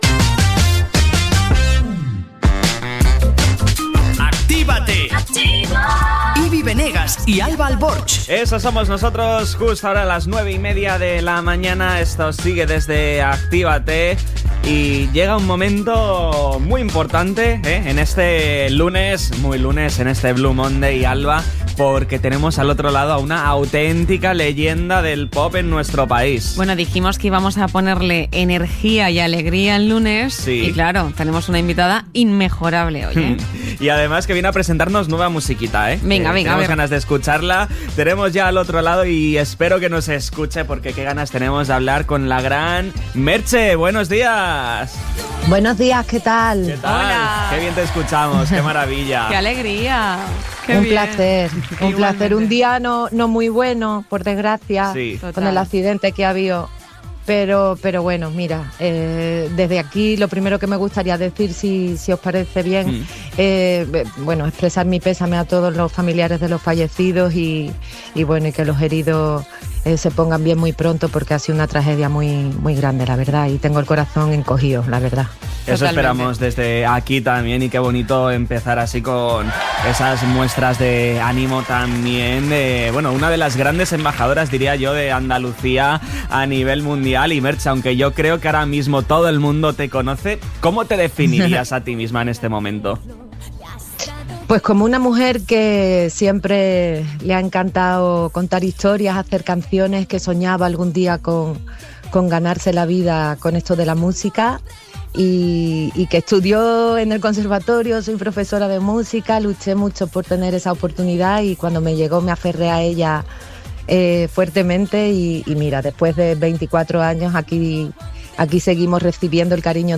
Merche ha inaugurado este lunes 19 una semana muy intensa en ACTÍVATE, el morning show de Activa FM.
ENTREVISTA-MERCHE.mp3